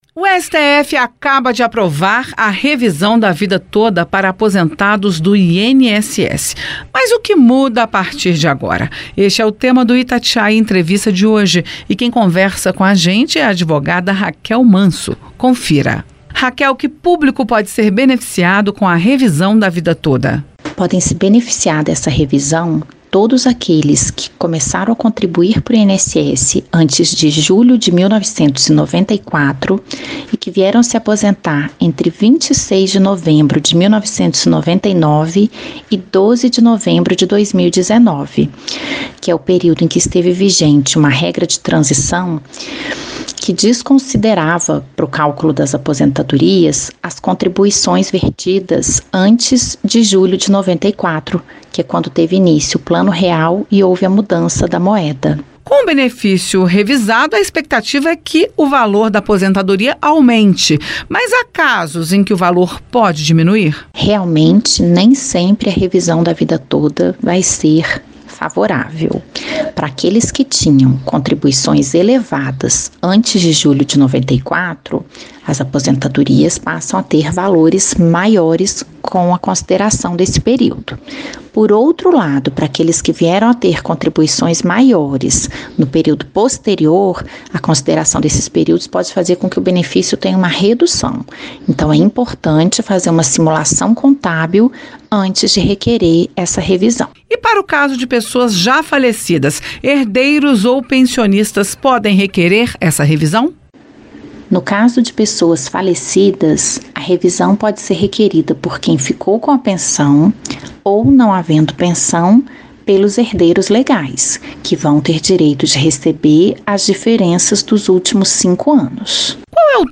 Entrevista
advogada-explica-inss-Revisao-da-Vida-Toda.mp3